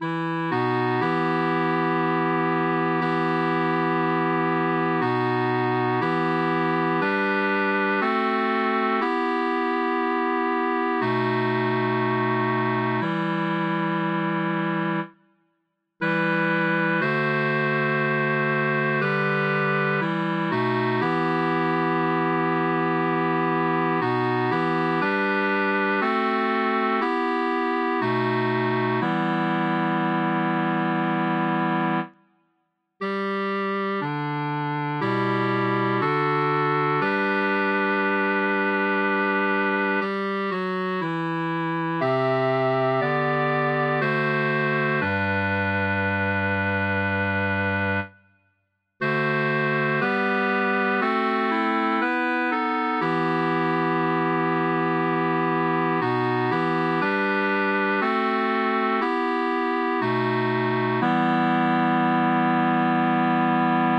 cor mixt